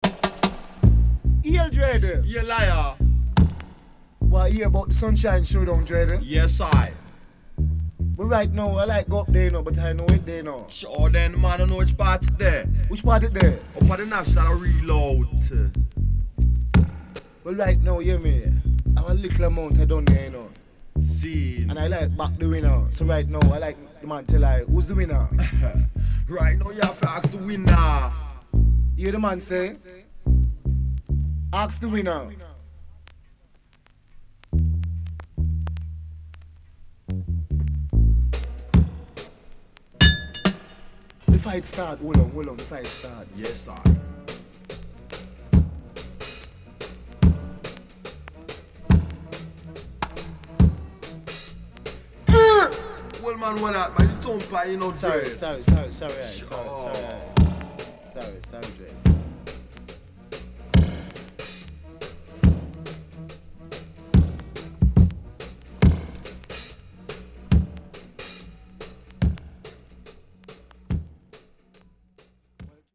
SIDE A:少しチリノイズ、プチパチノイズ入ります。